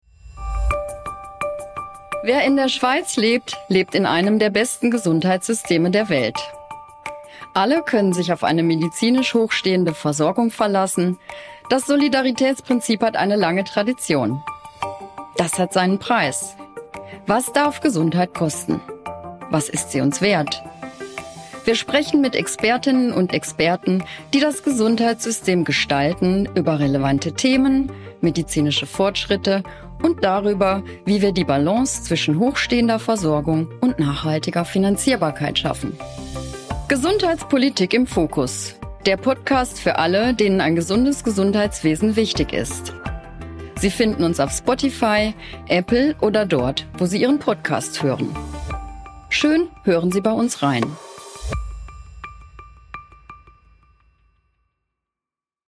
Trailer «Gesundheitspolitik im Fokus»